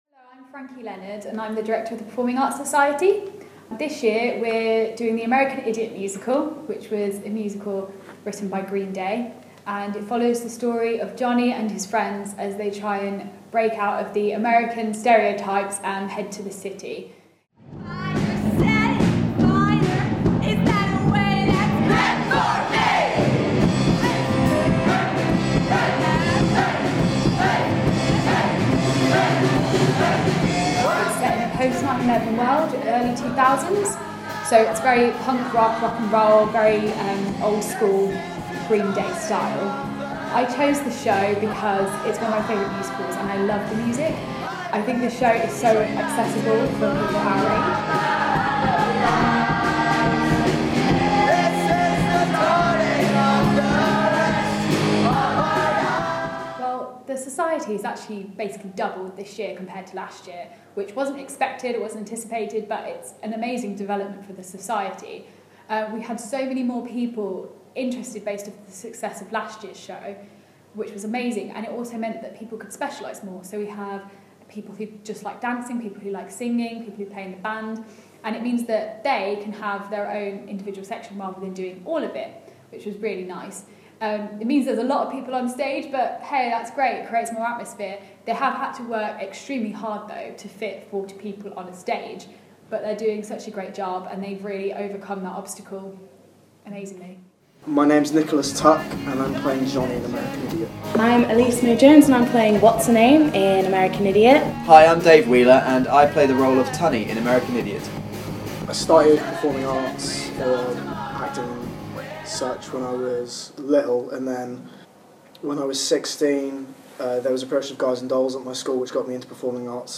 10 March 2017 BIRSt News Factual - Journalism